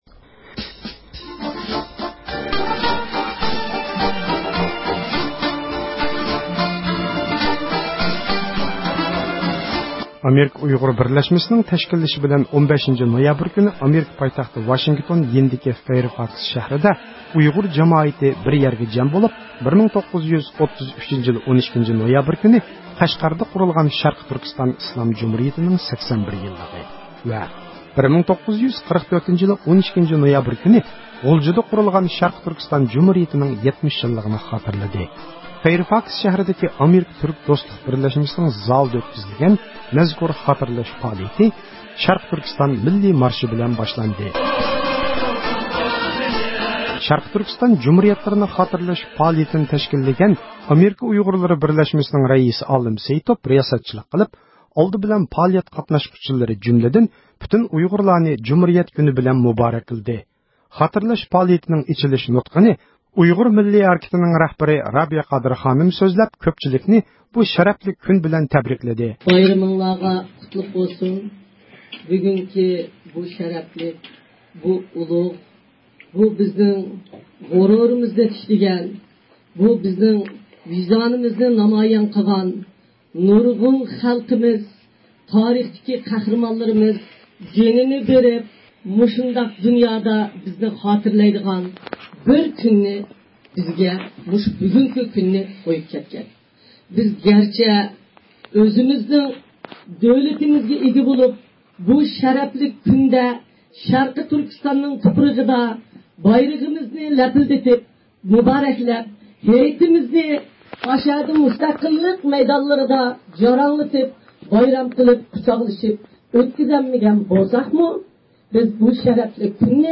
ئامېرىكا ئۇيغۇرلىرى بىرلەشمىسىنىڭ تەشكىللىشى بىلەن 15 – نويابىر كۈنى ئامېرىكا پايتەختى ۋاشىنگتون يېنىدىكى فەيىرفاكىس شەھىرىدە ئۇيغۇر جامائىتى بىر يەرگە جەم بولۇپ، 1933 – يىلى، 12 – نويابىر كۈنى قەشقەردە قۇرۇلغان شەرقىي تۈركىستان ئىسلام جۇمھۇرىيىتىنىڭ 81 يىللىقى ۋە 1944 – يىلى، 12 – نويابىر كۈنى غۇلجىدا قۇرۇلغان شەرقىي تۈركىستان جۇمھۇرىيىتىنىڭ 70 يىللىقىنى خاتىرىلىدى.
15-نويابىر كۈنى ئېىلپ بېرىلغان جۇمھۇرىيەت كۈنى پائالىيىتىدىن بىر كۆرۈنۈش